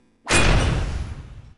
x1_battle_wate_attack.wav